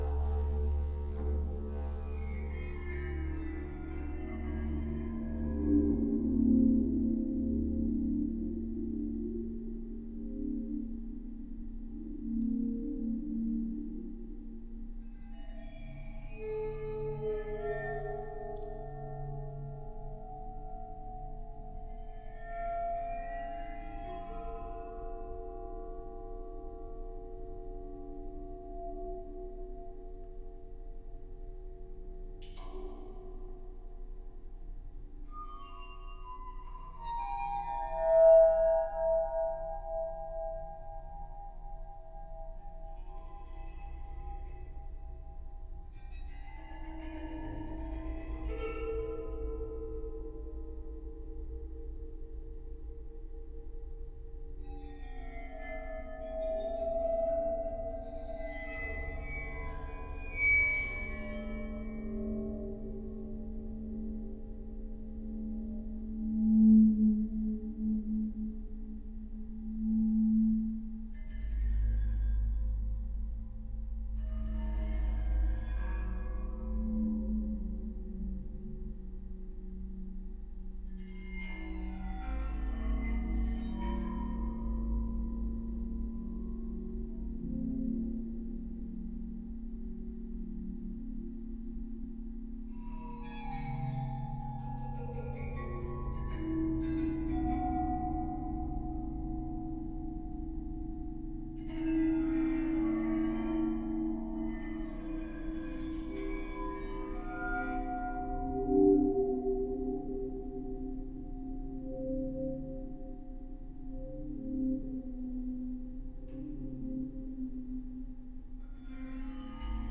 Waterphone
03-Waterphone.wav